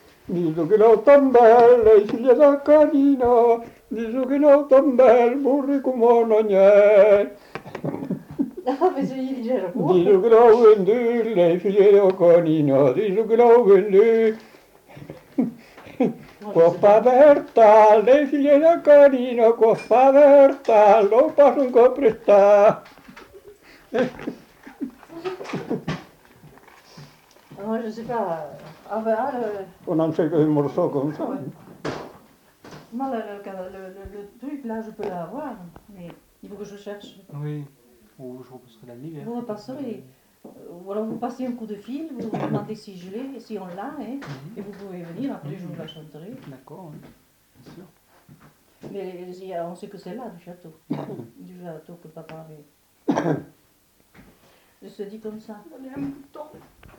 Aire culturelle : Viadène
Lieu : Lacroix-Barrez
Genre : chant
Effectif : 1
Type de voix : voix d'homme
Production du son : chanté
Danse : bourrée
Classification : satiriques, plaisantes diverses